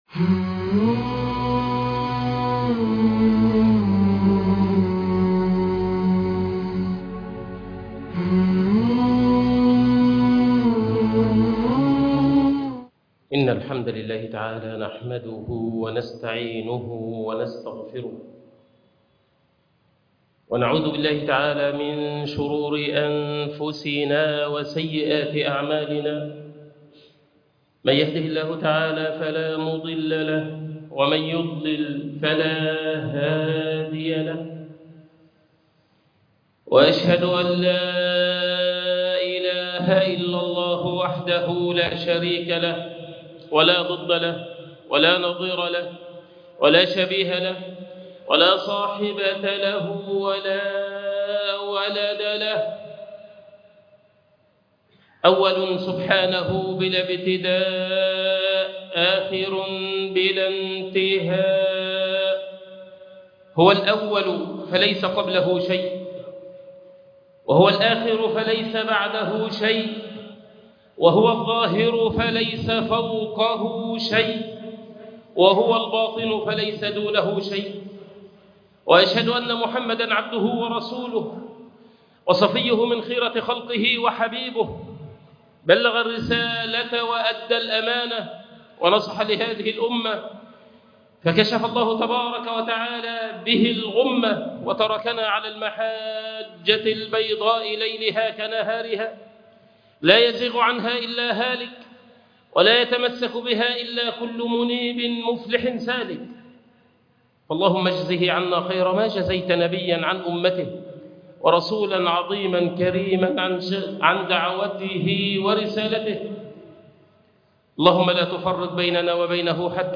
اسم الله الله خطبة جمعة